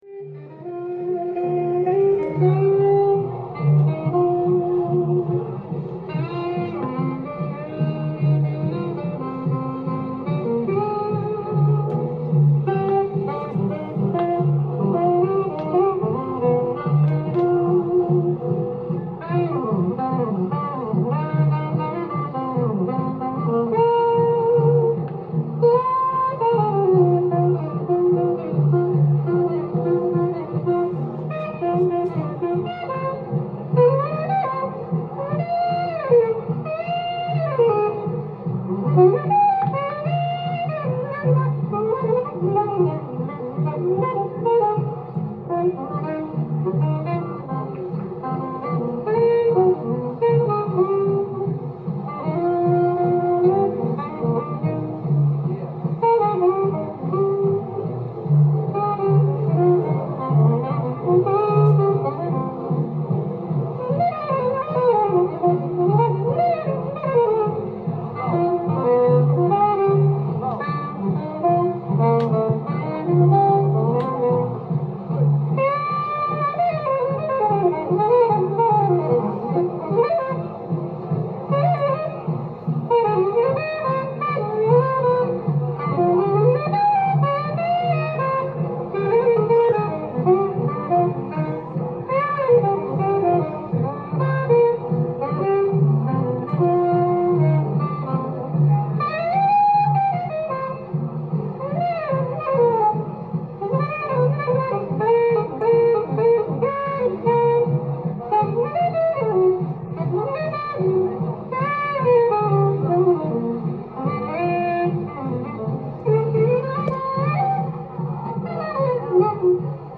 店頭で録音した音源の為、多少の外部音や音質の悪さはございますが、サンプルとしてご視聴ください。
50年ハーレムのセント・ニコラス・アリーナ出演時の演奏を収めた1枚。